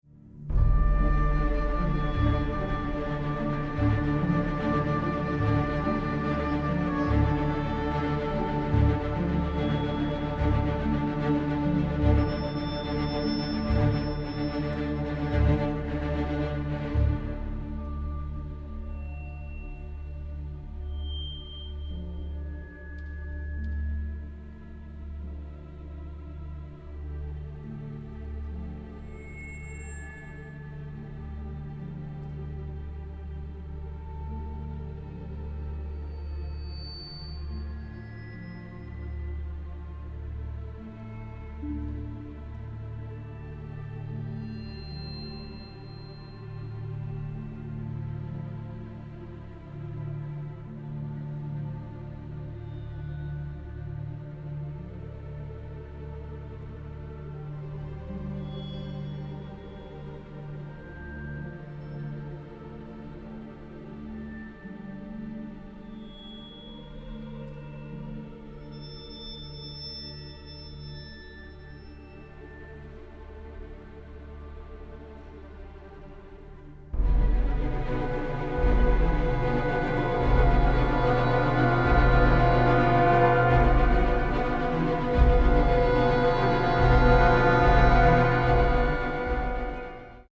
an energetic, dark and crude orchestral score